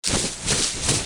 soldier ant.ogg